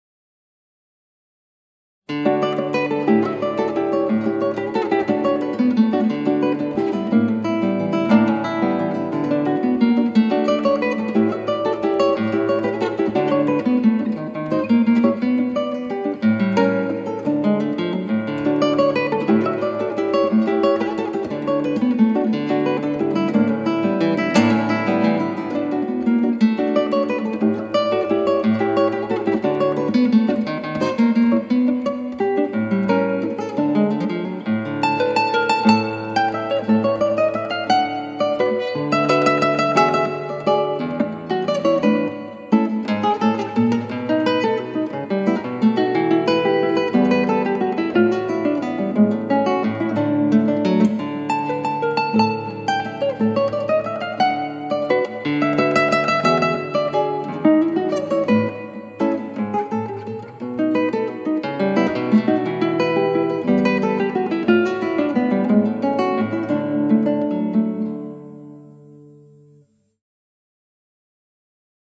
クラシックギター　ストリーミング　コンサート
だって美女にしてはいそがしいよ。というか俺早く弾きすぎ？
そう、カツカツとはっきり弾いたほうが良いようです。